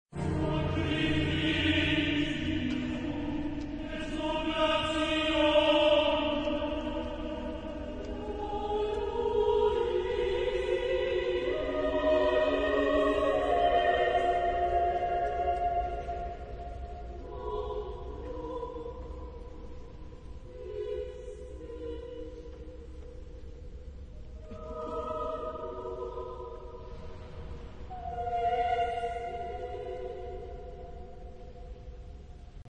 Genre-Style-Form: Sacred ; Motet
Type of Choir: SATB (div.)  (6 mixed voices )